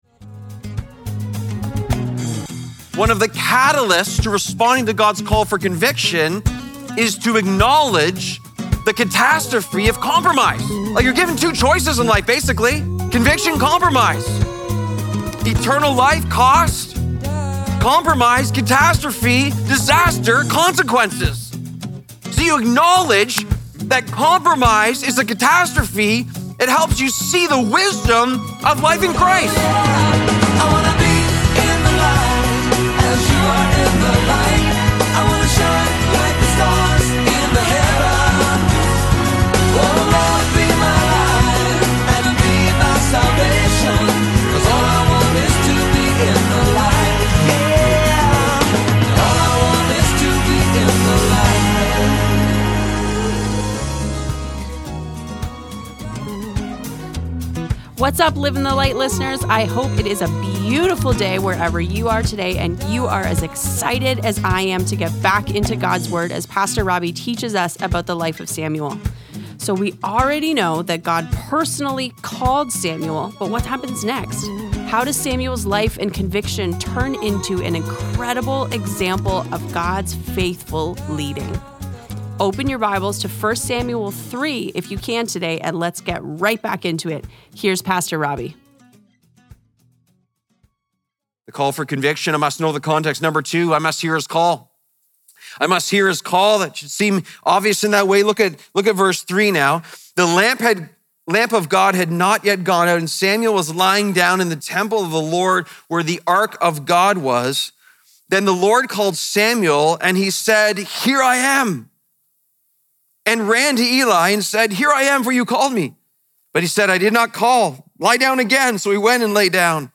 Live in the Light Daily Broadcast The Call for Conviction (Part 2 of 2) Mar 20 2026 | 00:29:30 1x 00:00 / 00:29:30 Subscribe Share Apple Podcasts Spotify Overcast RSS Feed Share Link Embed